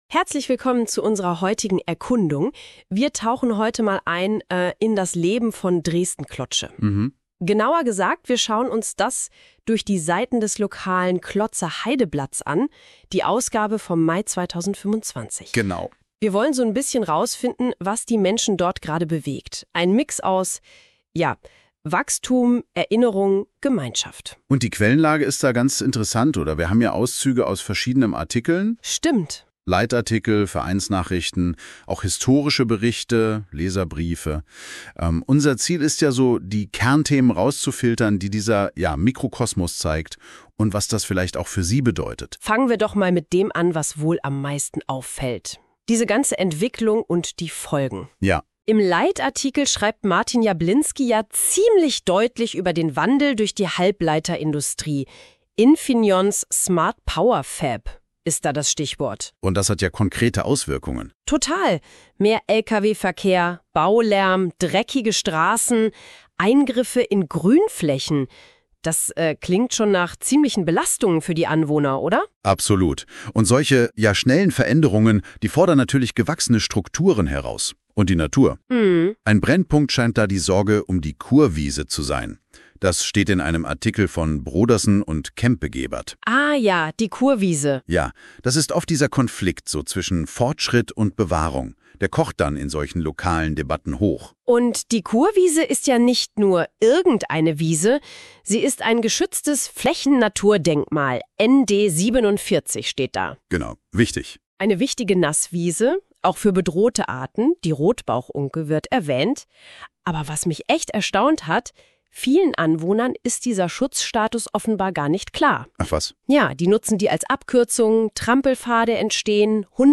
Die technischen Möglichkeiten von KI gehen jedoch weit darüber hinaus – das zeigt die Verarbeitung unserer 4. Ausgabe im Format eines sechsminütigen Podcasts. Zugegeben: nicht ganz fehlerfrei, aber dennoch ein spannender Einblick in das rasant wachsende Potenzial künstlicher Intelligenz.
KI_Podcast-online-audio-converter.com_.mp3